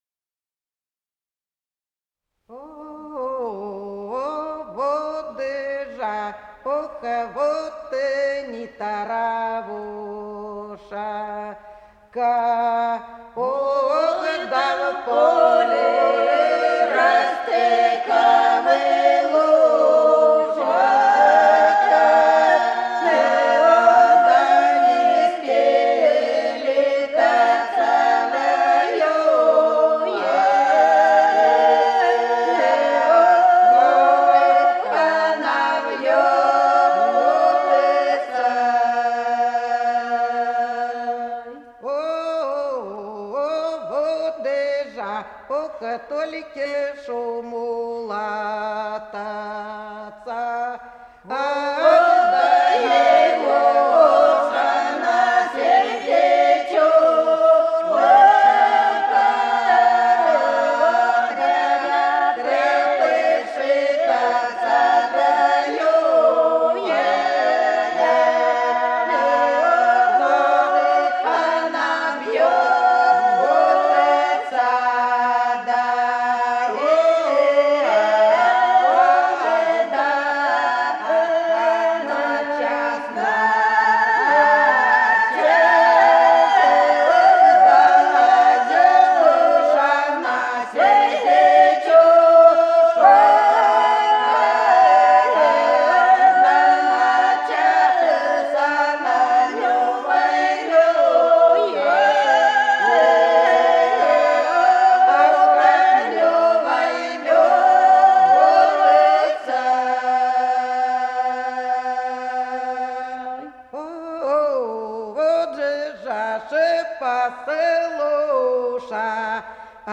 Голоса уходящего века (село Подсереднее) Вот же не травушка